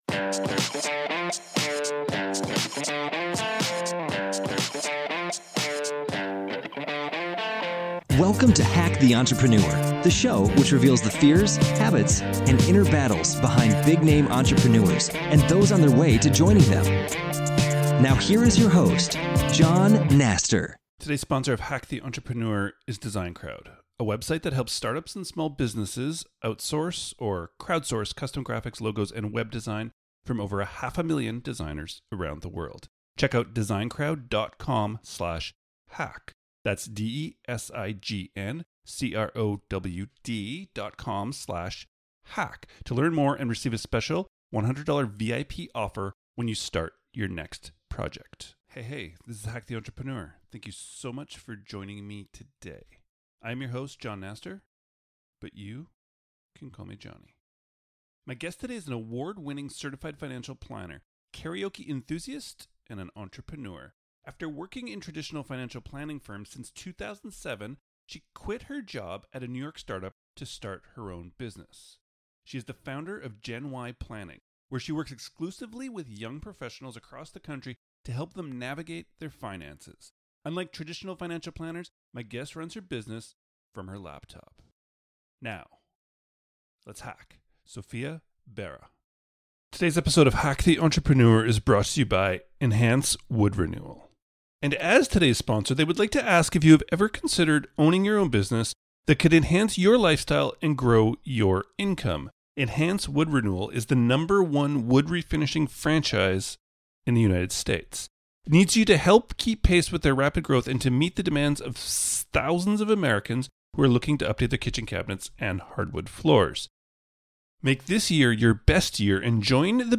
Unlike traditional Financial Planners, my guest runs her business from her laptop.